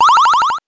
powerup_31.wav